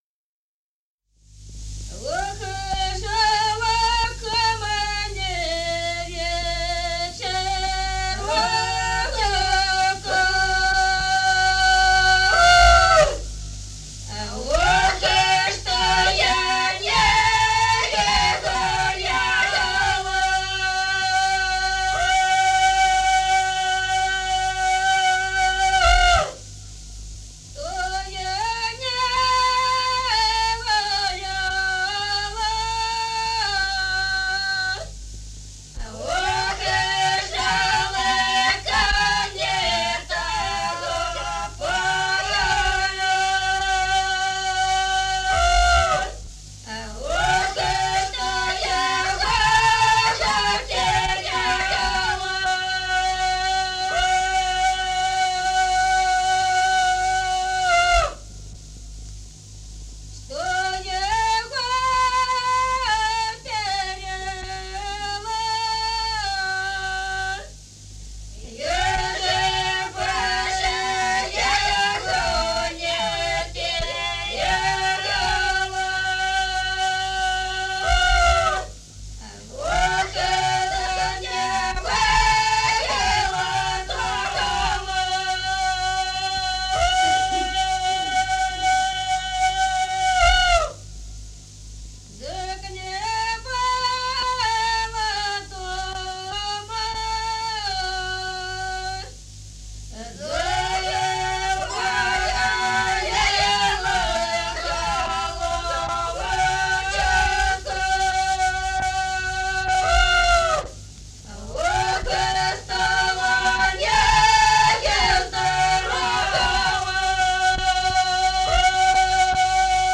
Народные песни Стародубского района «Ох, жалко мне вечерочка», жнивная.
(запев)
(подголосник)
с. Остроглядово.